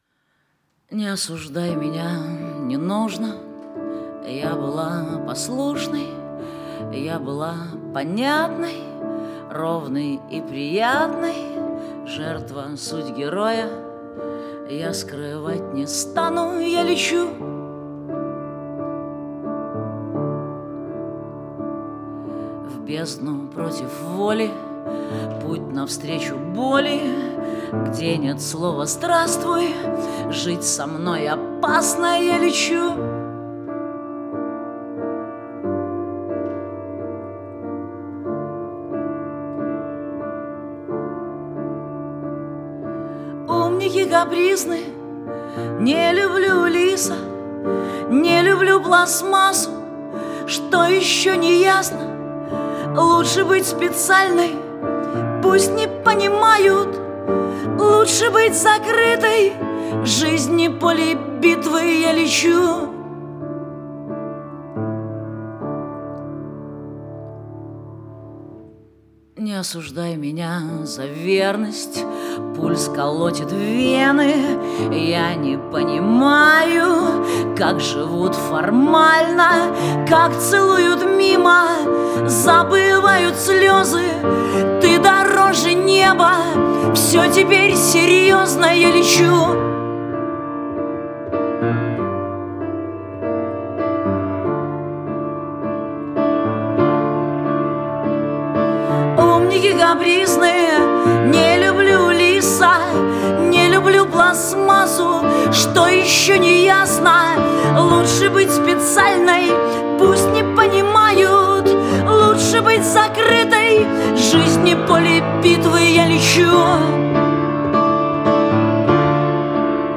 поп-рок
Настроение трека – легкое и uplifting
мелодичным инструментальным сопровождением